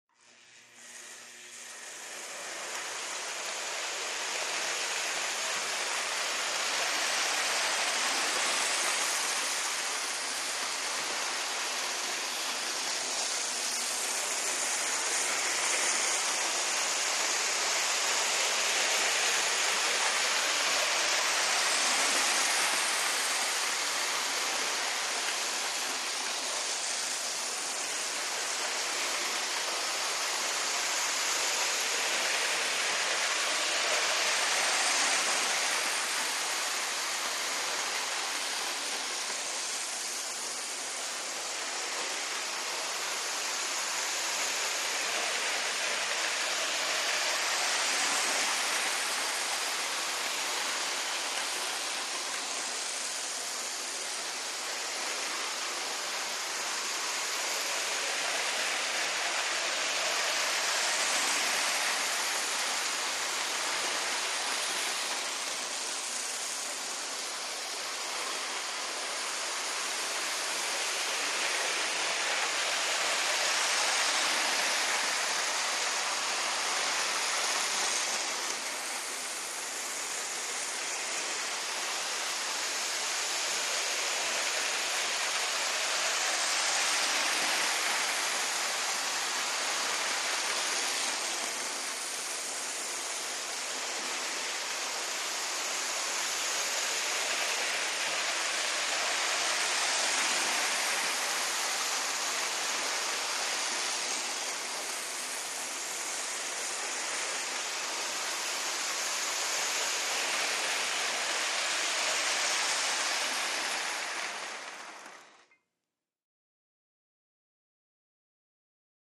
Toy Electric Train; Runs Around Track Steady.